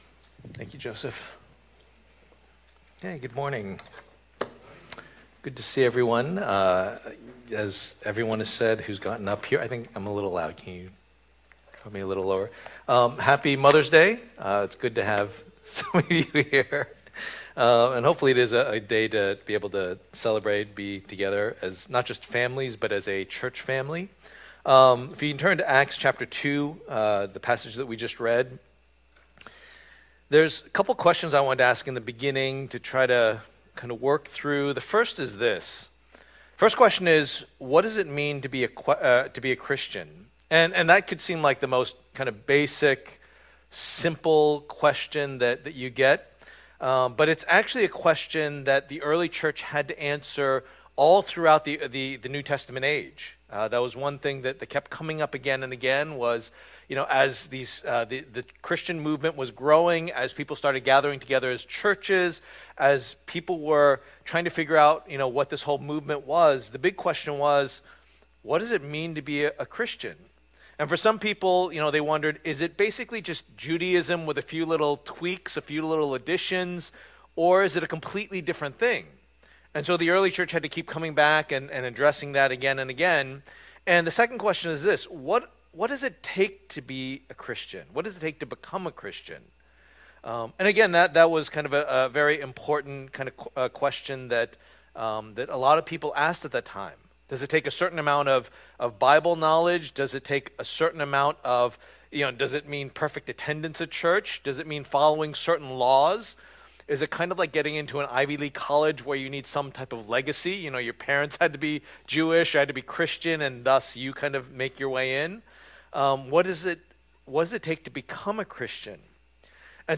The First Sermon